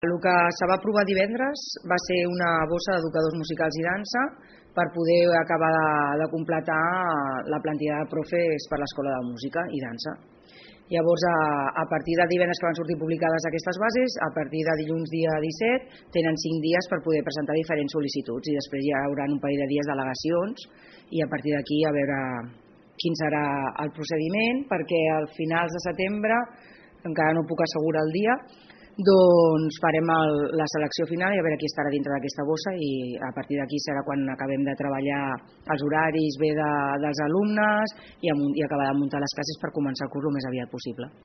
Rosa Garcia és regidora d’Educació de l’Ajuntament de Palafolls.